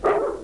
Barking Dog Sound Effect
Download a high-quality barking dog sound effect.
barking-dog.mp3